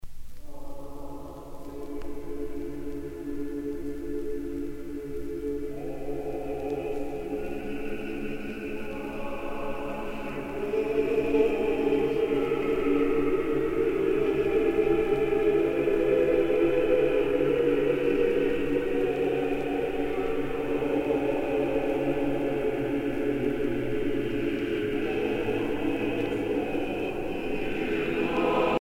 circonstance : dévotion, religion ;
Pièce musicale éditée